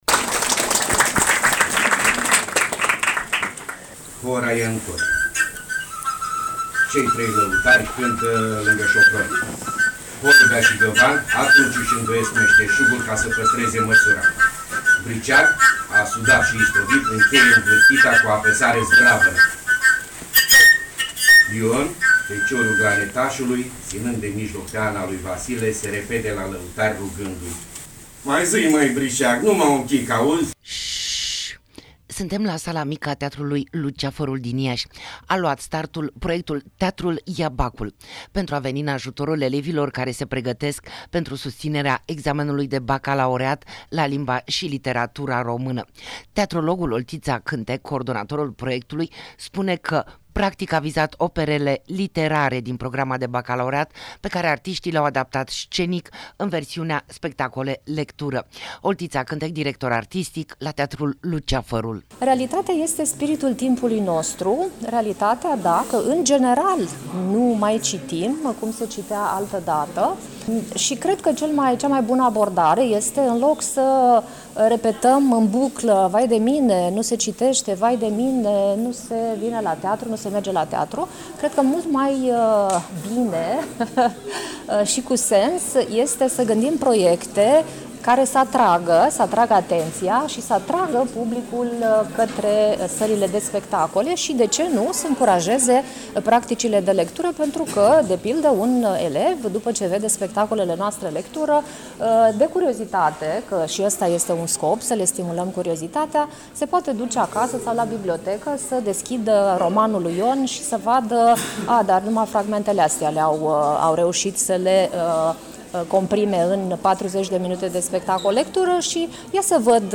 Reportaj-teatrul-ia-bacul.mp3